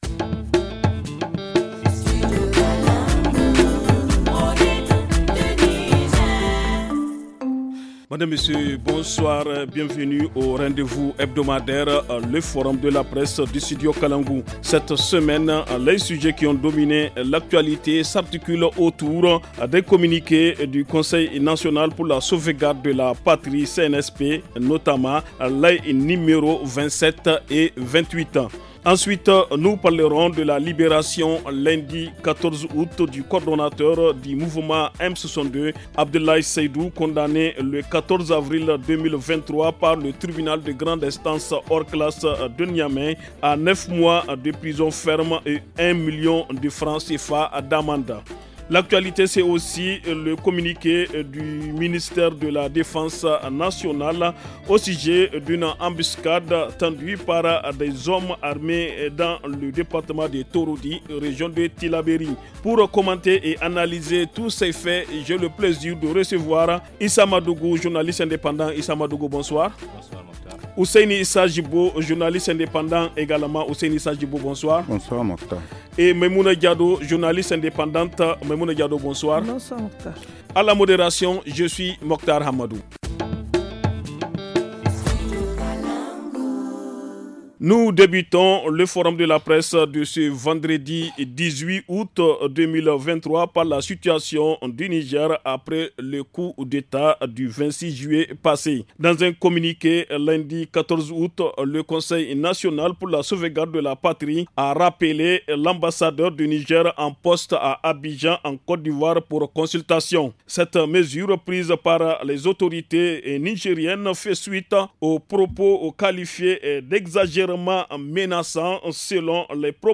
journaliste indépendante
journaliste indépendant